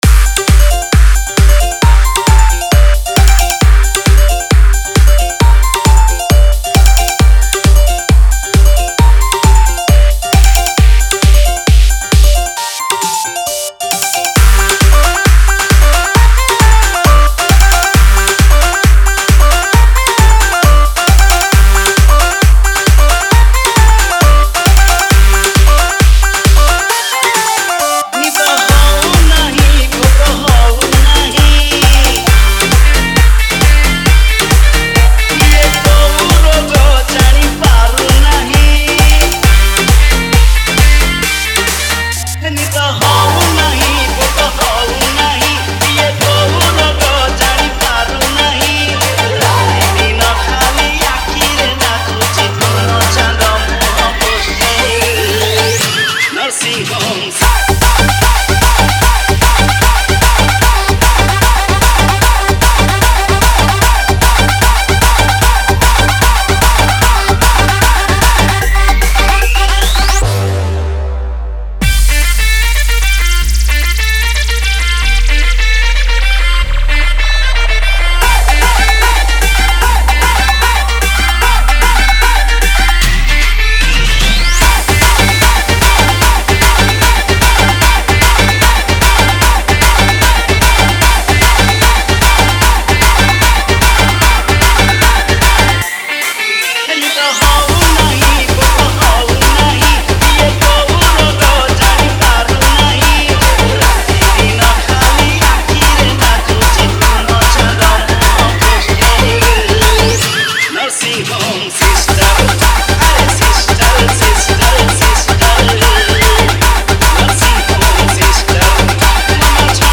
Ganesh Puja Special Dj